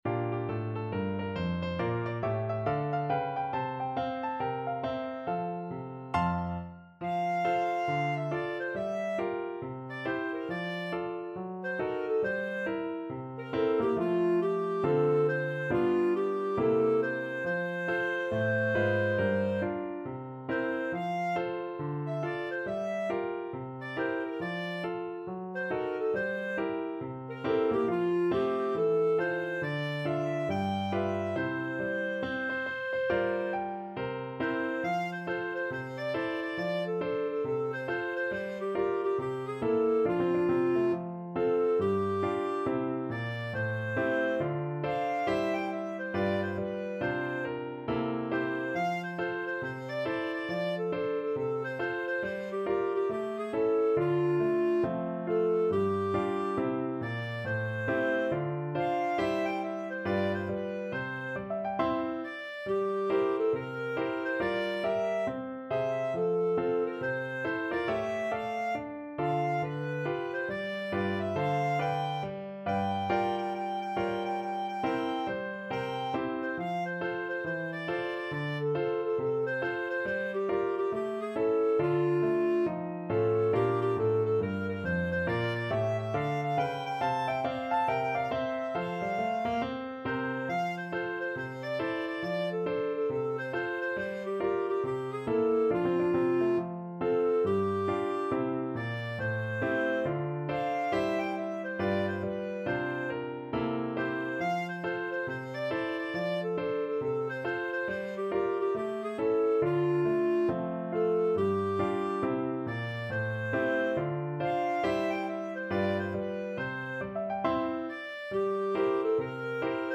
4/4 (View more 4/4 Music)
With a swing =c.69
Pop (View more Pop Clarinet Music)